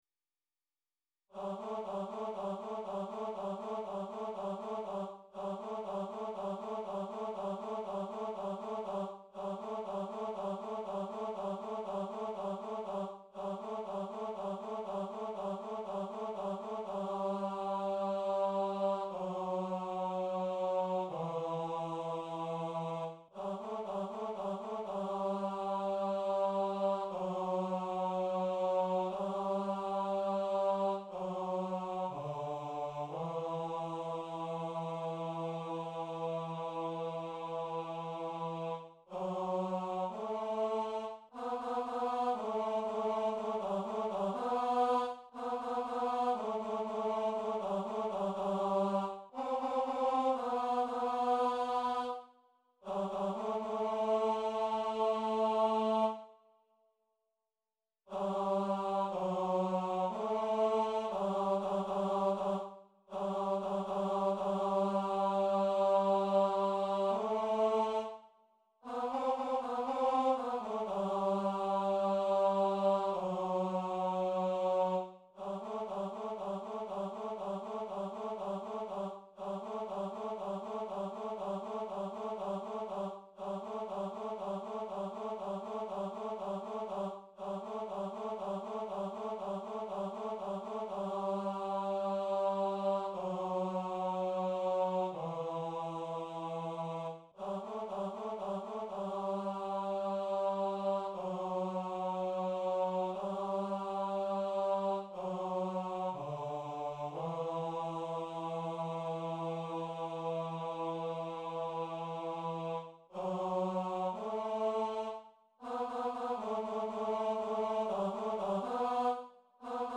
coro SATB